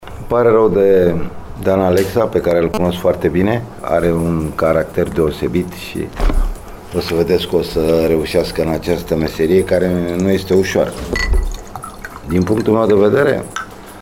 De altfel, o bună parte a conferinţei de presă pentru prezentarea noului antrenor a fost prilej de regrete faţă de încheierea “mandatului Alexa”.